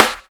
SNARE118.wav